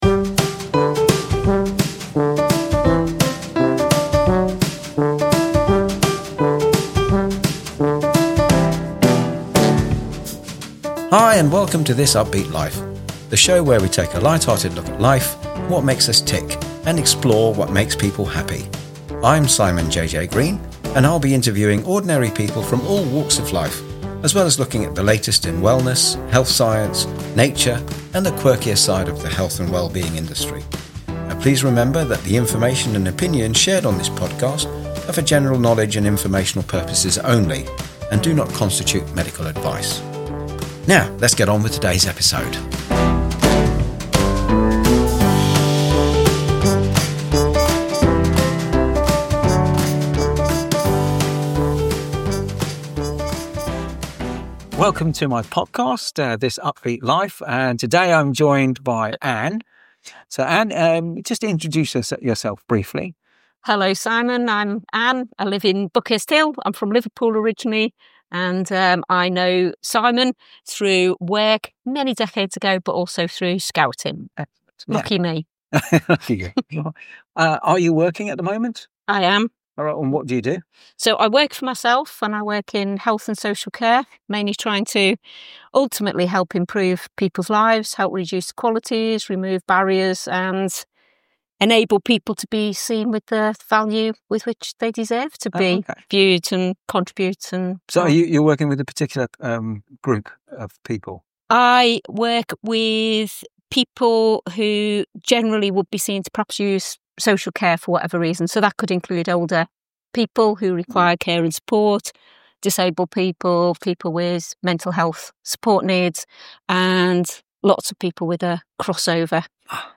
A Brilliant Interview